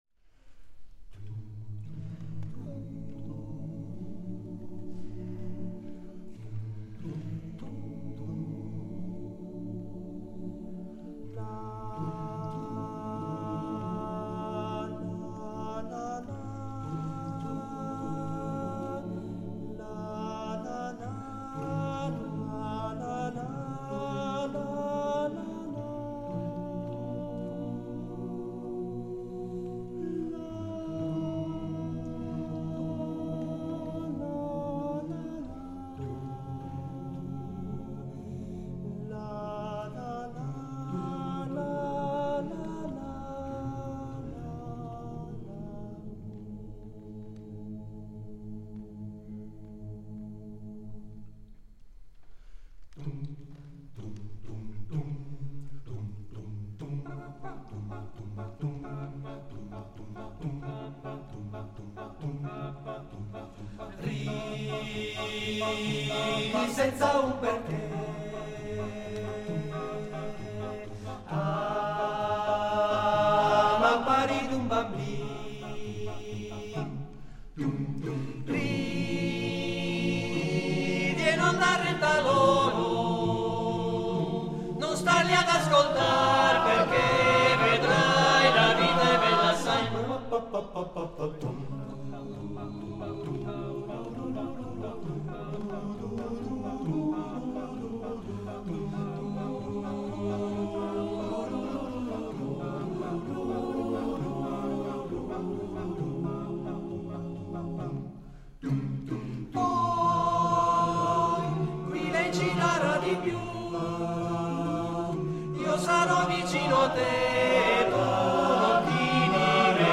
: Registrazione live